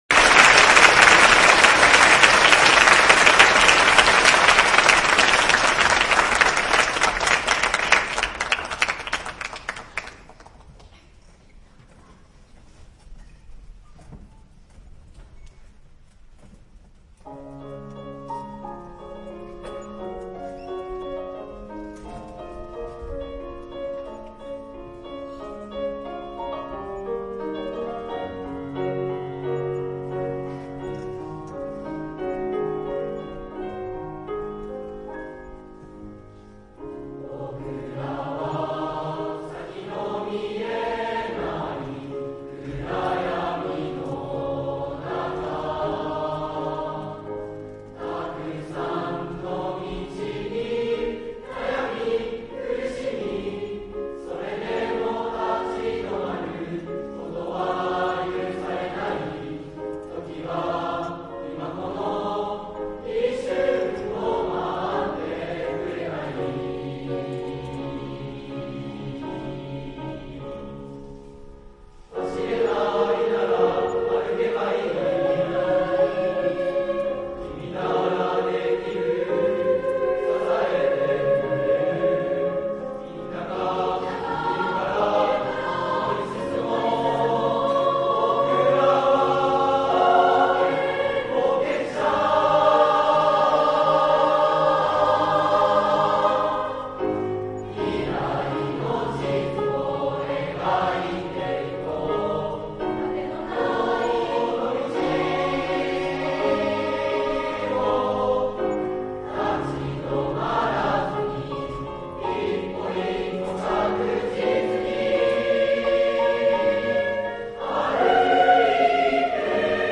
創作の過程で「最後の部分はテンポを落として、しっとり歌おう」などと楽曲のイメージが広がったようです。
４．「冒険者」を卒業式で熱唱 与進中学校3年生の皆さんが、2016年3月17日に卒業式を迎えられました。生徒たちの作ったメロディはプロの音楽家に編曲を依頼し、混声3部合唱になりました。
涙を拭いながらの大合唱は、会場を大きな感動で包みました。